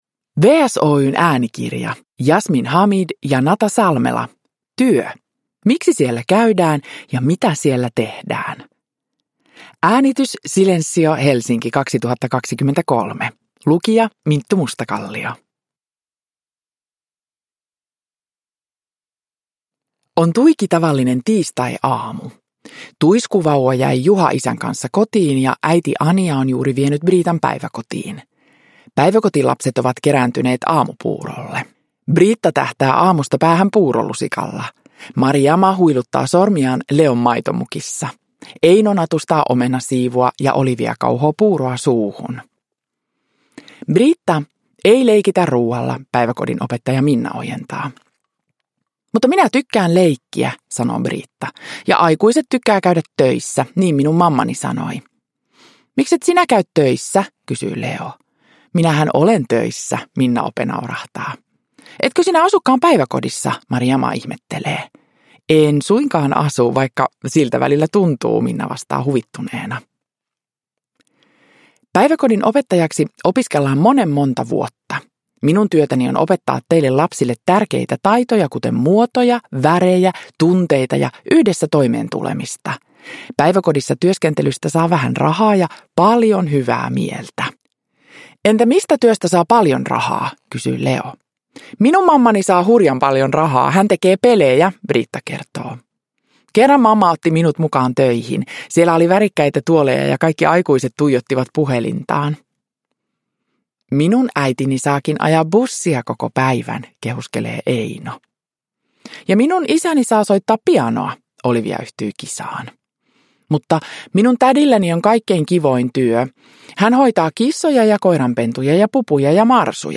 Produkttyp: Digitala böcker
Uppläsare: Minttu Mustakallio